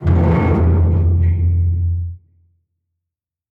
StructureCrunch1.ogg